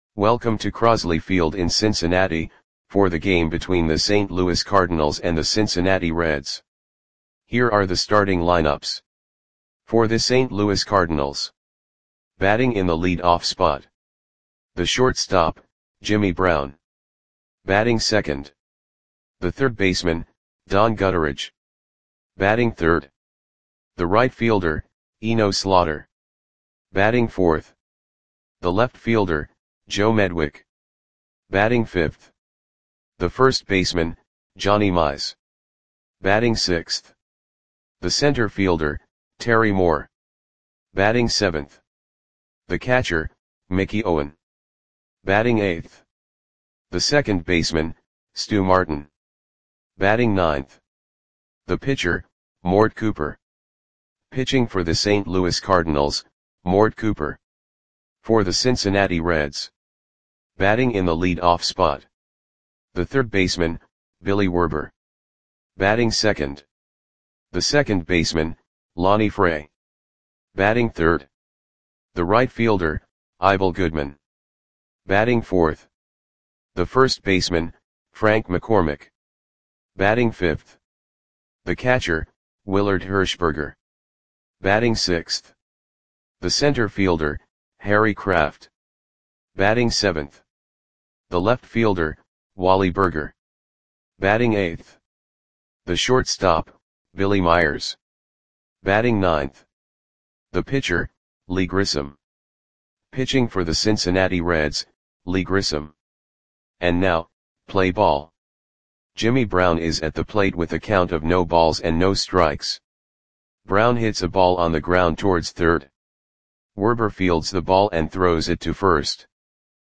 Audio Play-by-Play for Cincinnati Reds on September 26, 1939
Click the button below to listen to the audio play-by-play.